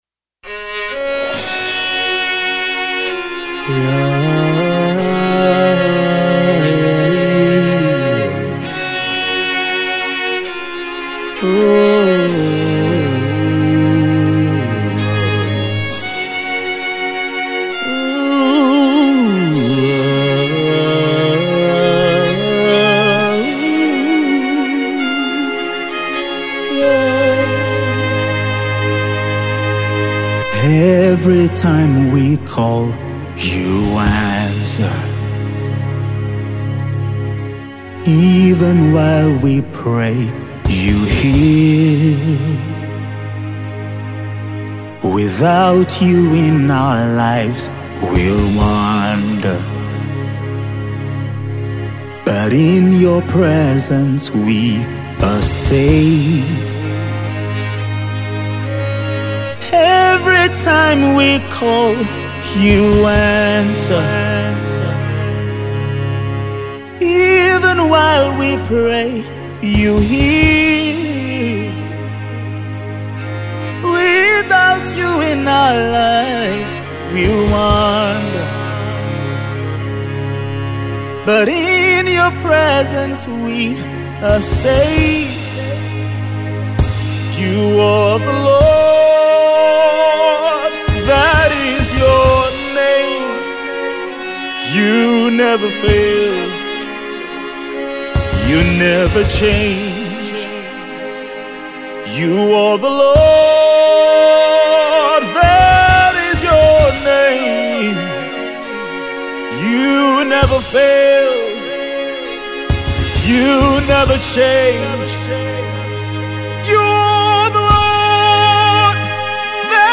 deep worship